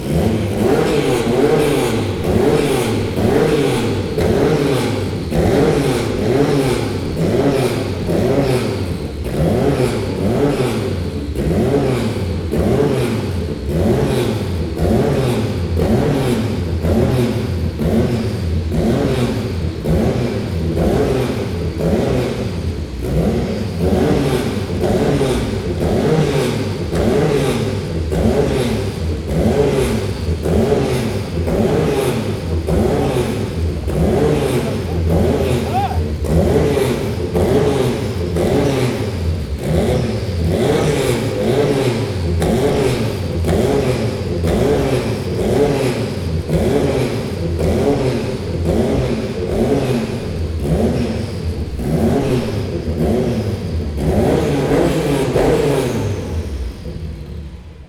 Porsche-962-C.mp3